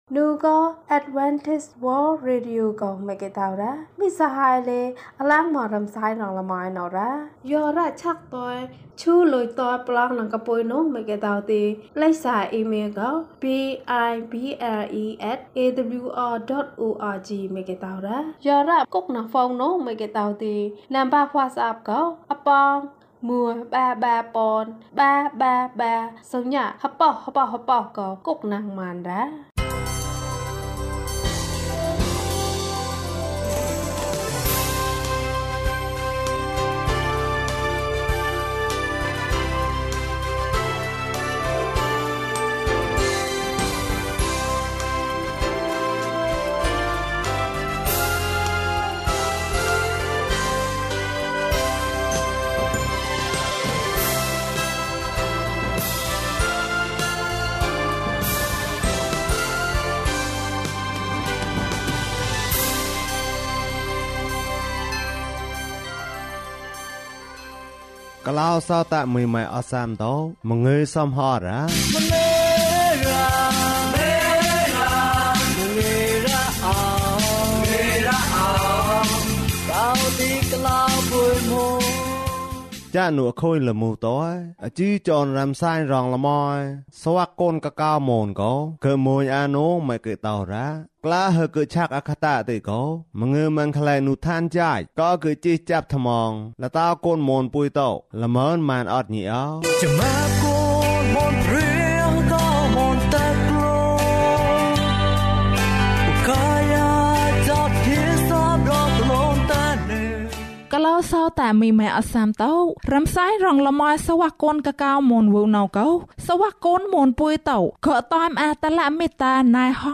ပျော်ရွှင်သော မိသားစုဘ၀၊ ၀၁၊ ကျန်းမာခြင်းအကြောင်းအရာ။ ဓမ္မသီချင်း။ တရားဒေသနာ။